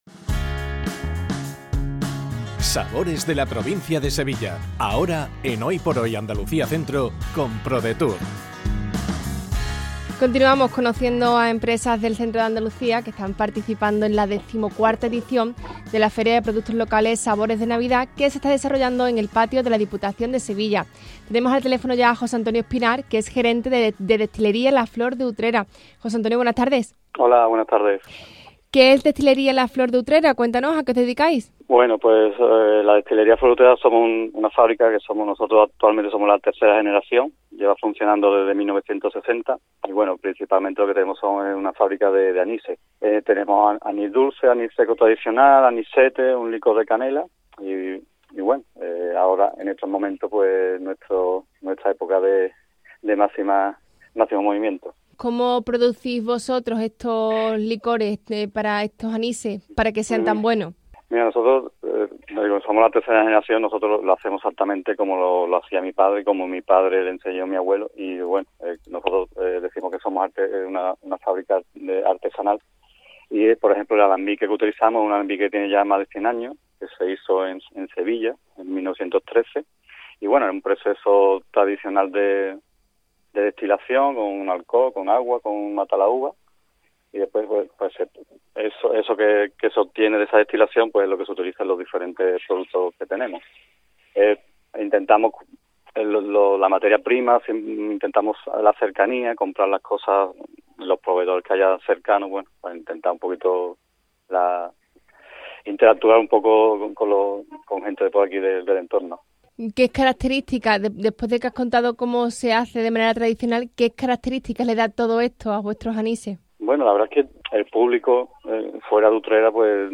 ENTREVISTA | Destilería La Flor de Utrera